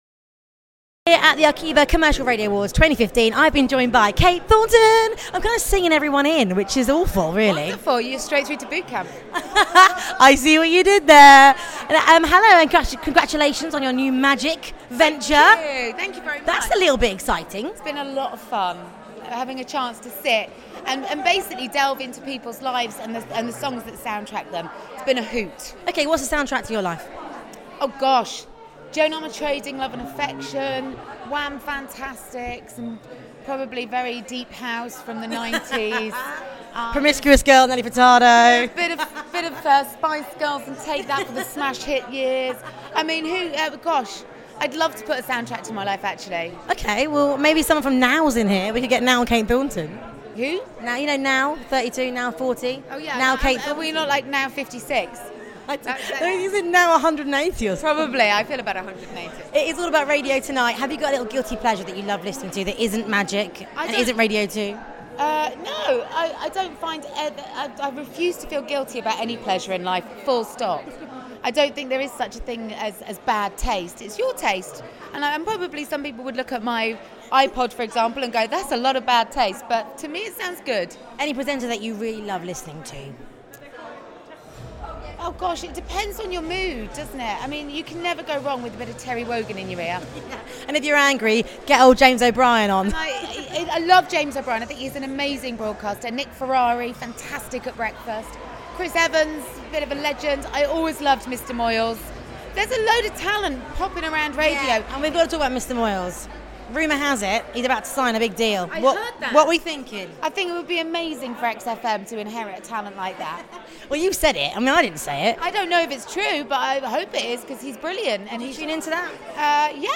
Kate Thornton here at the Arqivas